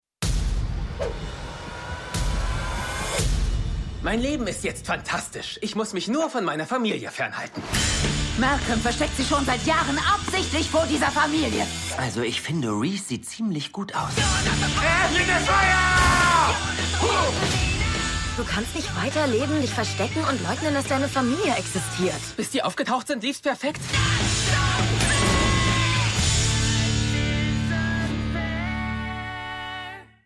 Bei uns wurden alle Stimmen umbesetzt.
MalcolmTrailer.mp3